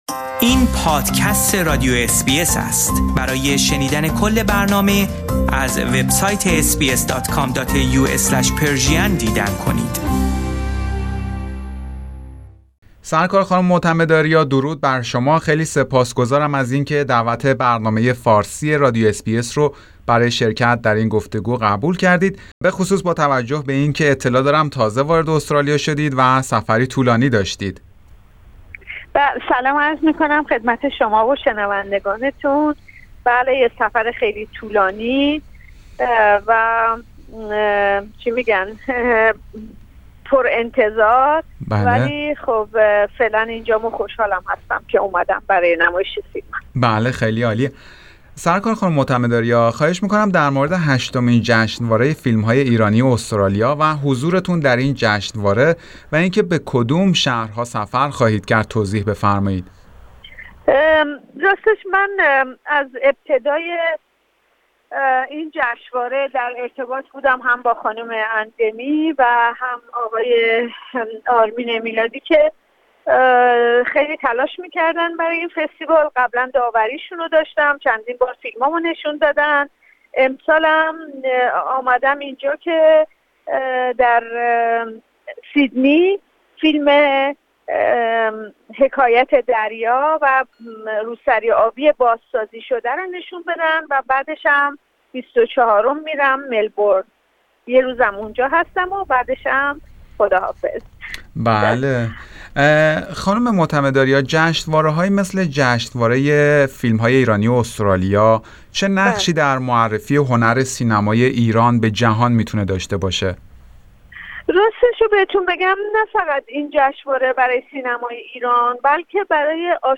همزمان با برگزاری این جشنواره، گفتگویی داشتیم با خانم معتمد آریا و به بررسی برخی فیلم هایی که در این جشنواره نمایش داده خواهد شد و وضعیت کلی سینمای ایران پرداختیم.